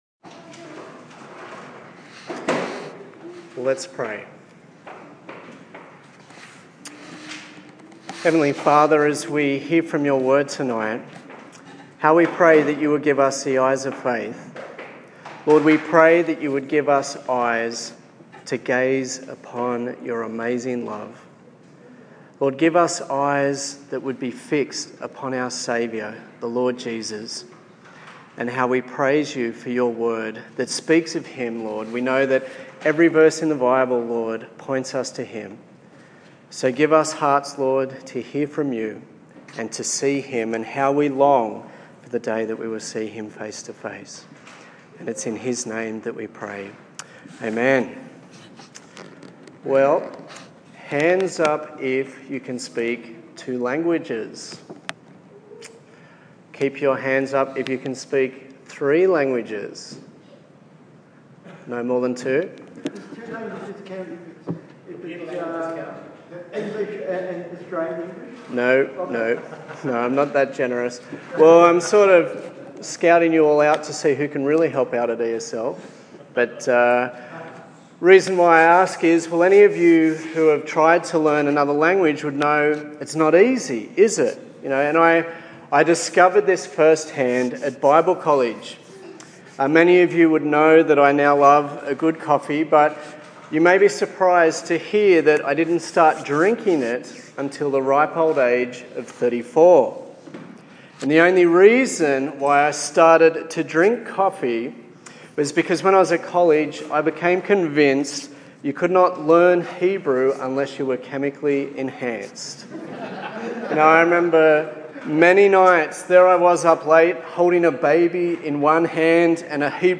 The third sermon in the series on Ruth